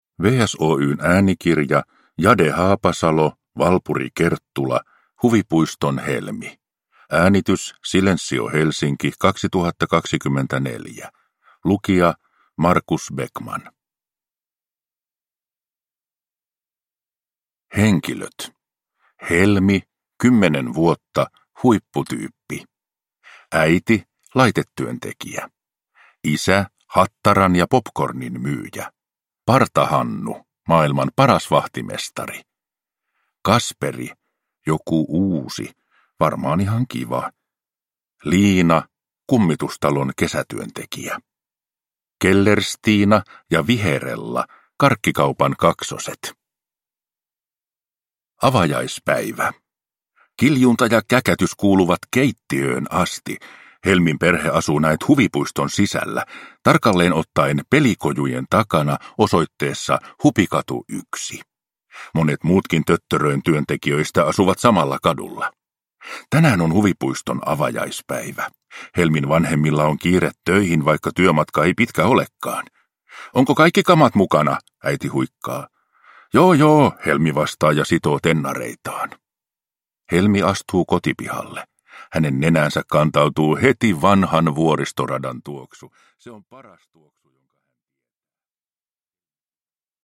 Huvipuiston Helmi – Ljudbok